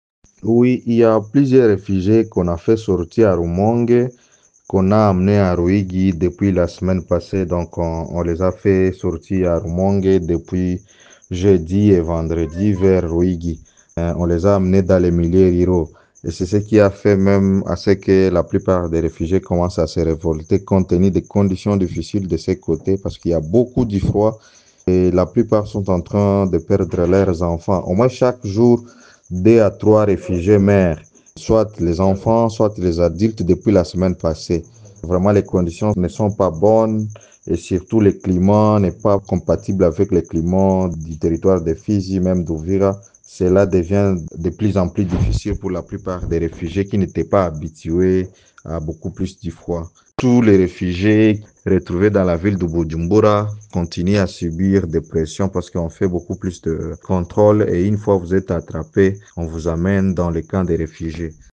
Suivez la déclaration de ce réfugié: